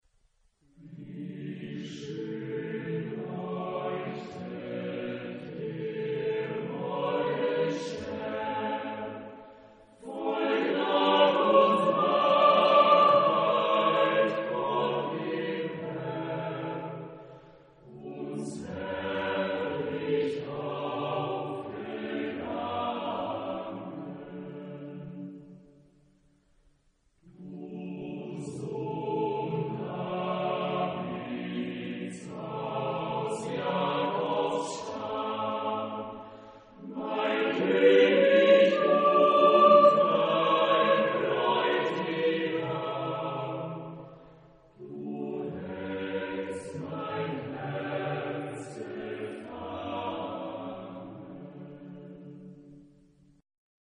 Genre-Style-Forme : Sacré ; Choral
Caractère de la pièce : solennel ; calme
Type de choeur : SATB  (4 voix mixtes )
Tonalité : ré majeur